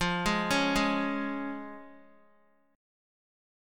Fm#5 chord